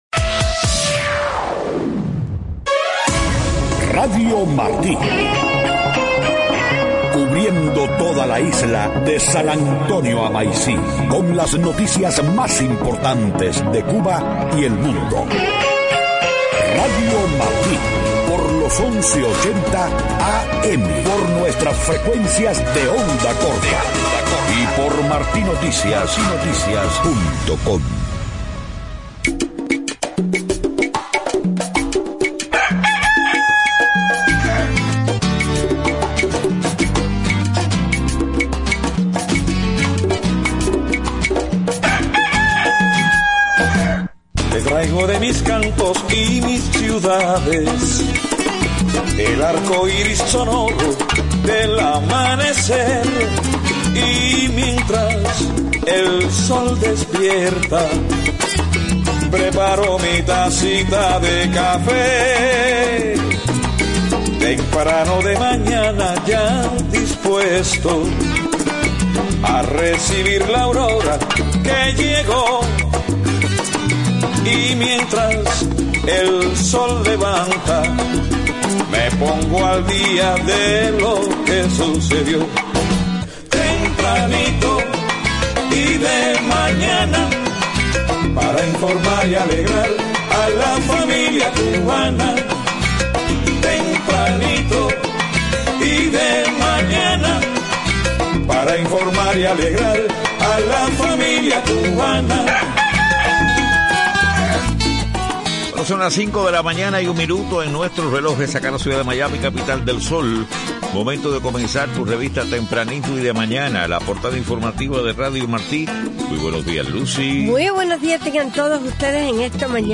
5:00 a.m. Noticias: EEUU exhorta al Gobierno de Cuba a respetar los derechos fundamentales y a liberar a los activistas detenidos arbitrariamente. El presidente Obama pide a China medidas concretas para reducir tensiones en el Mar de la China Meridional y el ciberespacio. Liberan en Venezuela a dos estudiantes que estuvieron en prisión por más de un año.